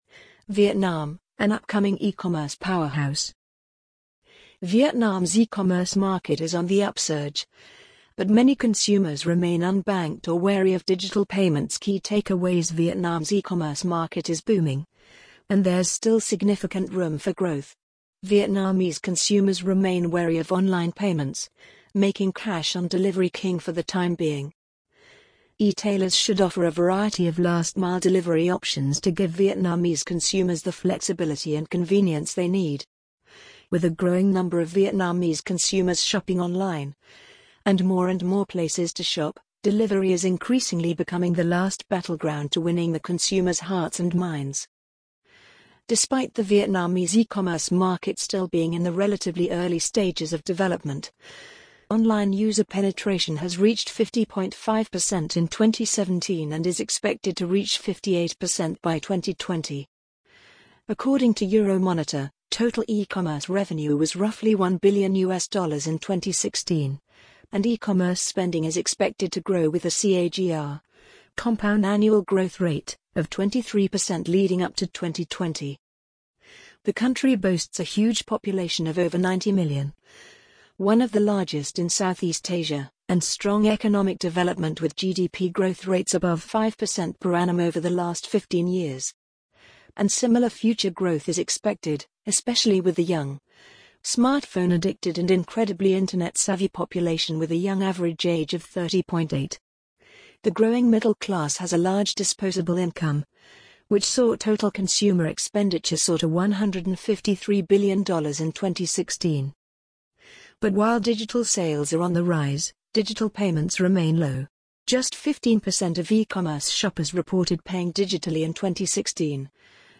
amazon_polly_1295.mp3